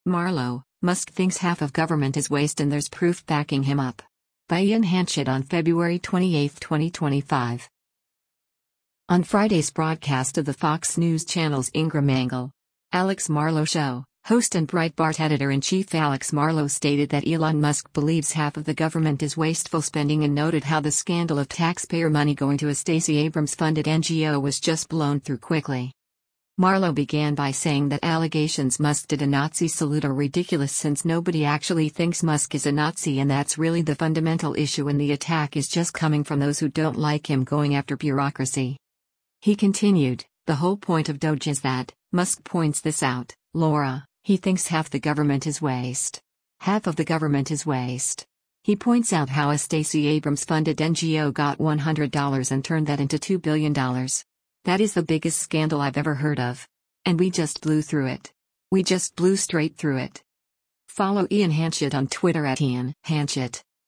On Friday’s broadcast of the Fox News Channel’s “Ingraham Angle,” “Alex Marlow Show,” host and Breitbart Editor-in-Chief Alex Marlow stated that Elon Musk believes half of the government is wasteful spending and noted how the scandal of taxpayer money going to a Stacey Abrams-funded NGO was just blown through quickly.